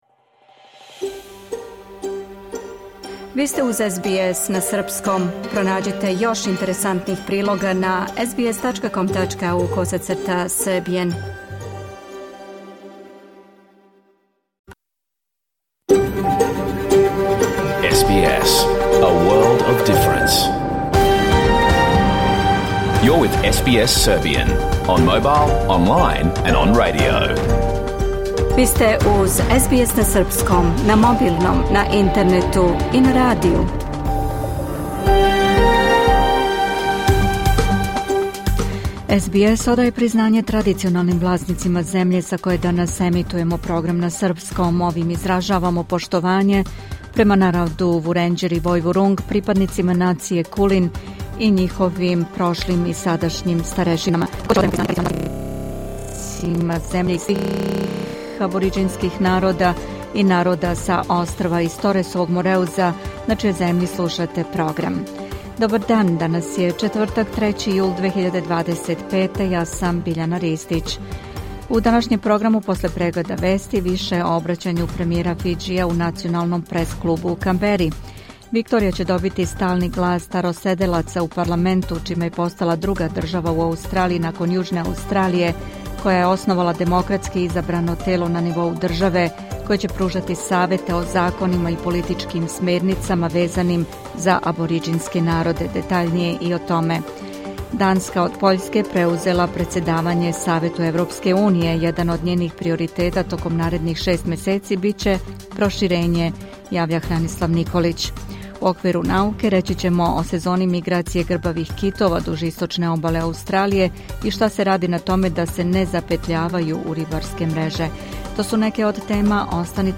Програм емитован уживо 3. јула 2025. године